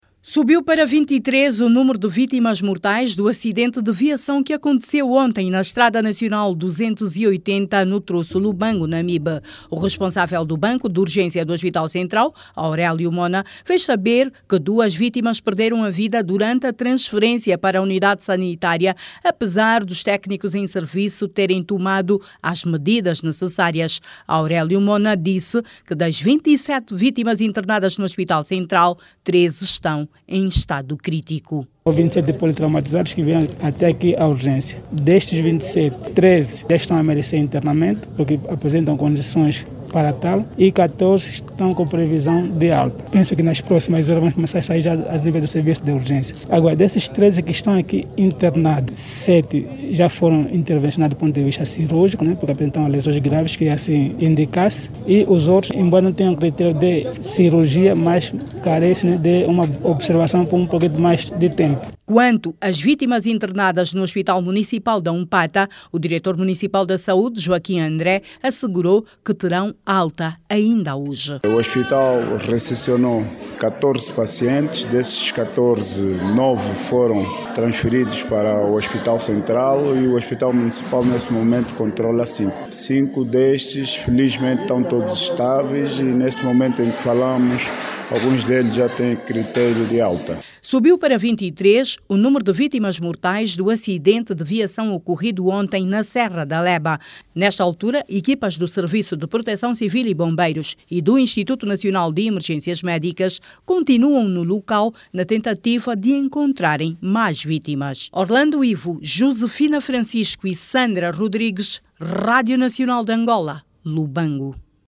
As vítimas do acidente de viação ocorrido ontem, quarta-feira(16), na Serra da Leba, subiu para 23 mortos, 27 feridos e 13 dos quais em estado grave. Clique no áudio abaixo e ouça a reportagem